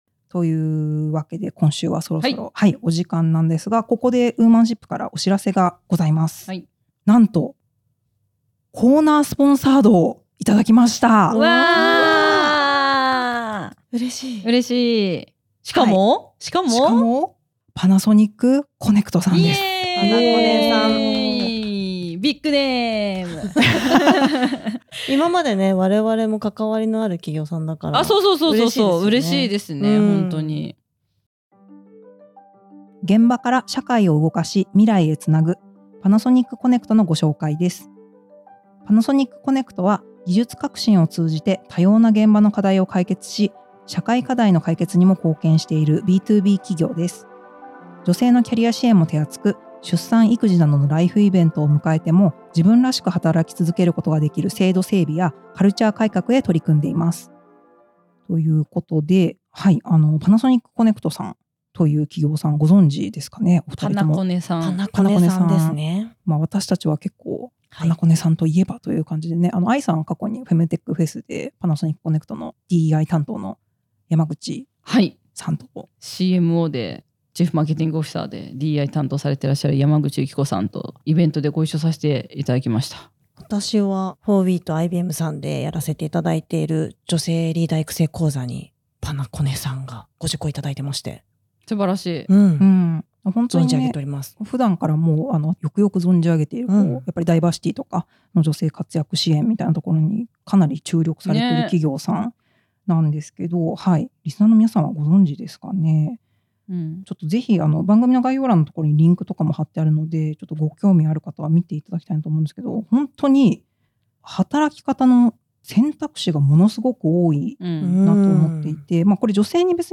パナソニック コネクト株式会社は、女性のキャリアや働き方をテーマにしたポッドキャスト番組「WOMANSHIP -はたらく私たちのお悩みサミット-」にホストリードアドを配信しました。